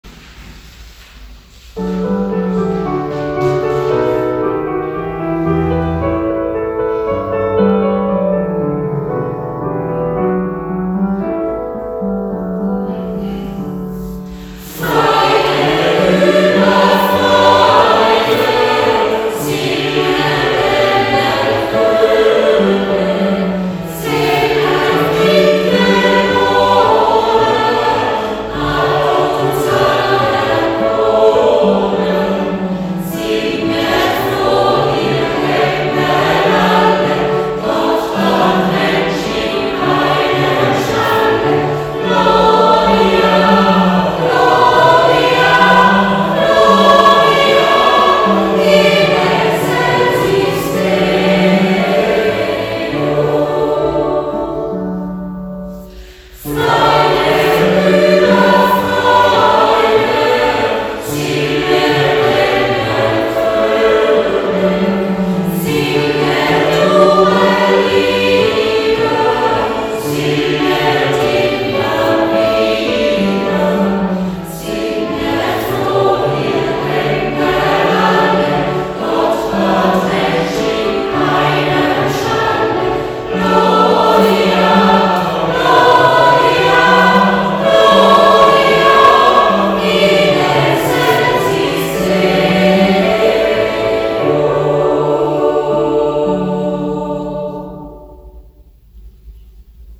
In insgesamt 9 Proben üben wir bekannte, aber auch weniger bekannte, mehrstimmige Weihnachtslieder ein. Am Weihnachtsgottesdienst vom 25.12.2025 gestaltet der Chor mit den Liedern den musikalischen Teil des Festgottesdienstes.